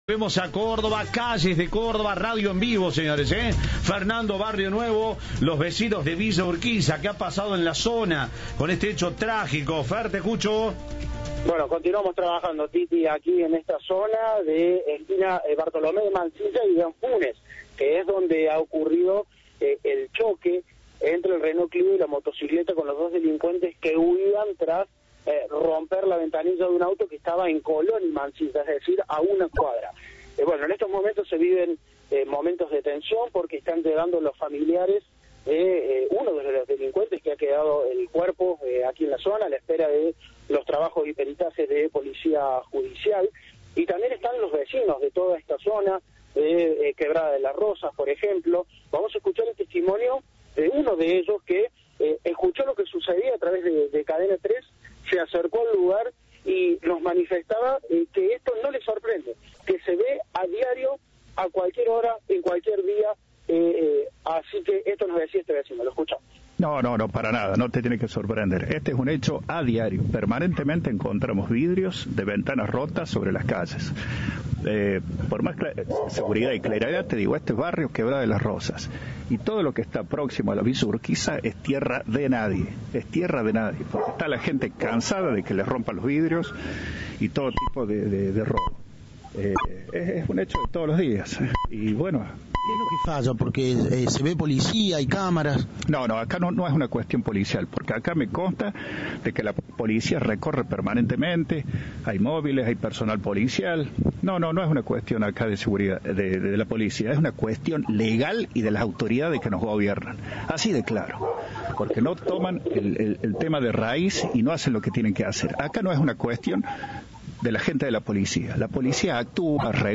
Un vecino que también presenció el intento de robo, fuga y muerte este sábado habló con Cadena 3 y aseguró que "Todo lo próximo a Villa Urquiza es tierra de nadie".